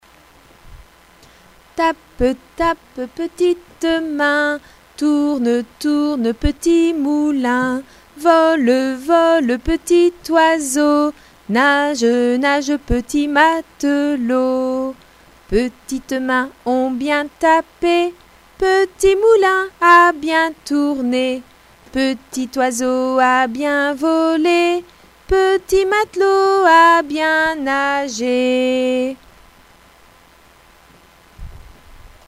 is a great singer. We recorded a number of children's songs in November 2002 with myself at the keyboard.